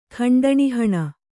♪ khaṇḍaṇi haṇa